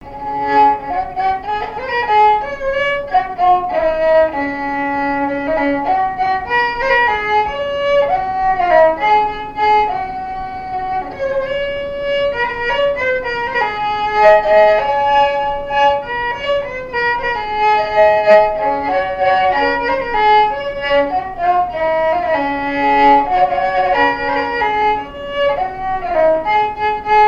Répertoire de marches de noce et de danse
Pièce musicale inédite